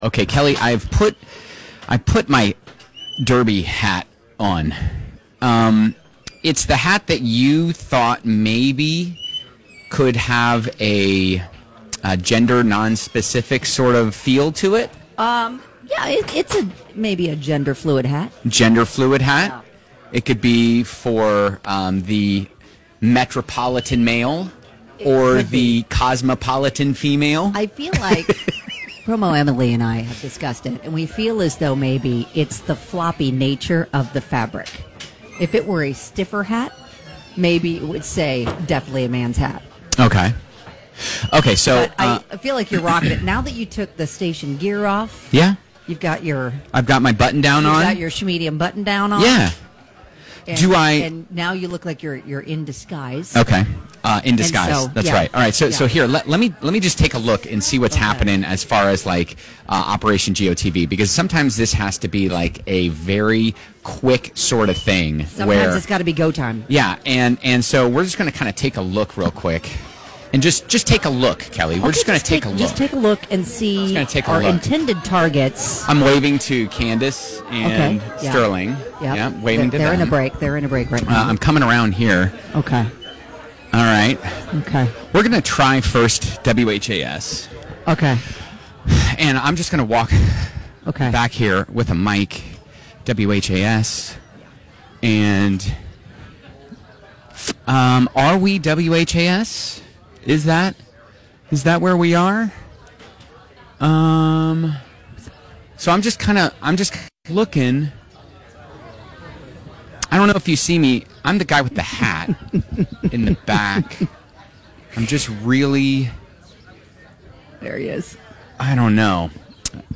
It's a tradition to try and wonder into the live shots of TV while we're all gathered together at Churchill Downs!